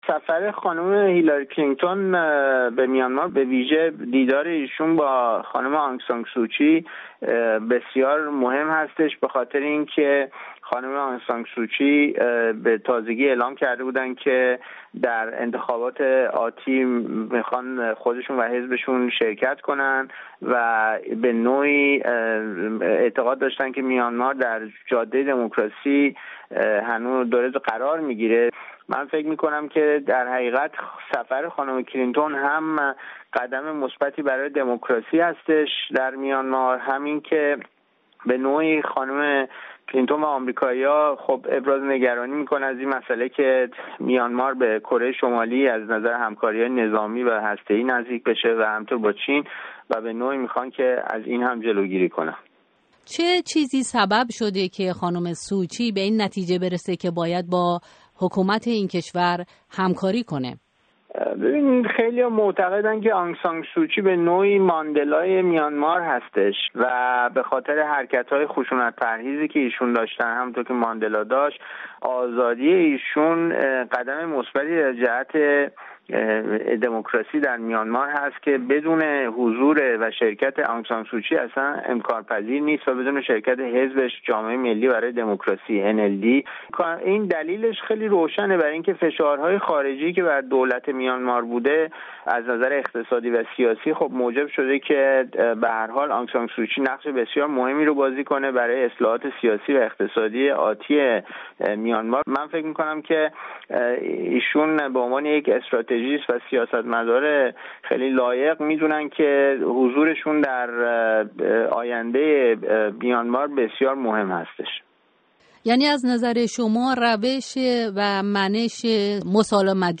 هیلاری کلینتون، وزیر خارجه آمریکا در روزهای گذشته دیداری تاریخی از میانمار، برمه سابق، داشت و از جمله با آن سان سوچی، رهبر مخالفان میانمار، دیدار کرد. در رابطه با این سفر، رامین جهانبگلو، جامعه‌شناس ایرانی و مبلغ شیوه مسالمت‌جویانه مبارزات سیاسی، به پرسش‌های رادیو فردا پاسخ داده است.
گفت‌وگوی